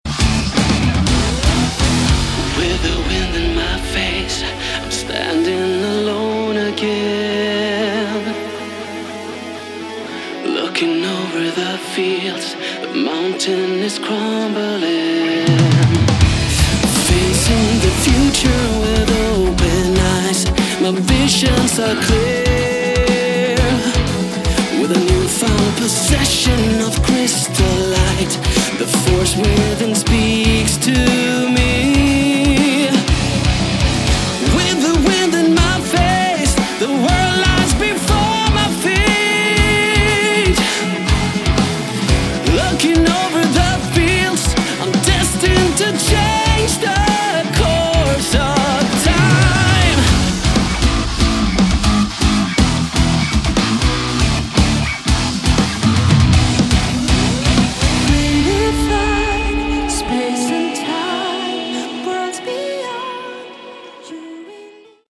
Category: Melodic Metal / Prog Metal
Guitars
Drums
Bass
Keyboards
Vocals